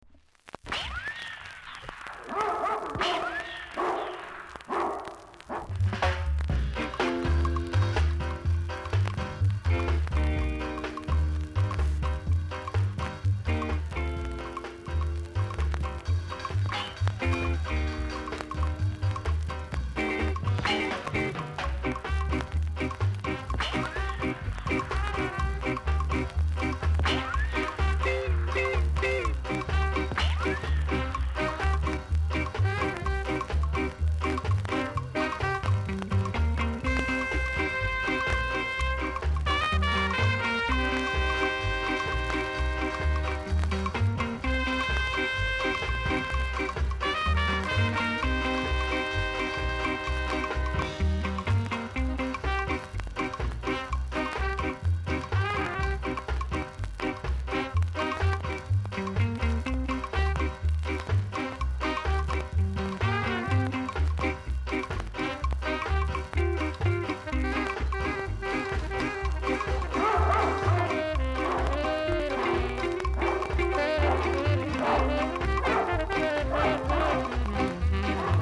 ※全体的にチリ、パチノイズがあります。